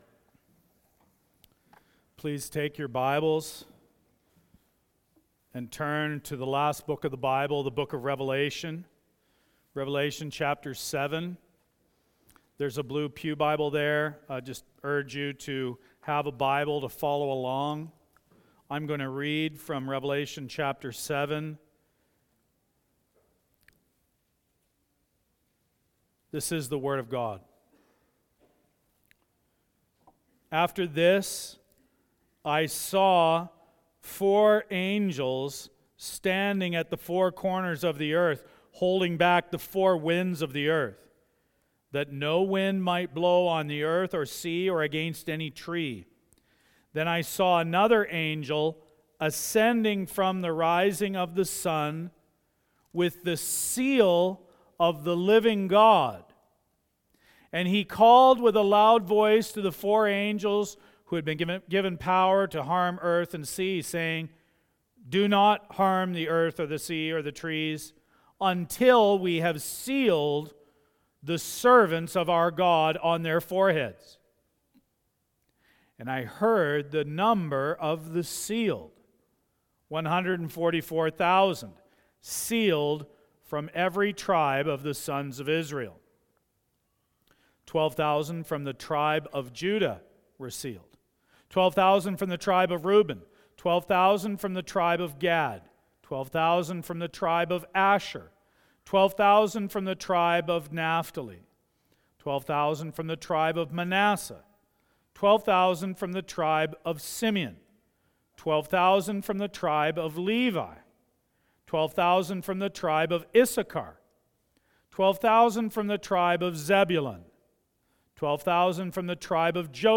Audio recordings of teaching from Calvary Grace Church of Calgary.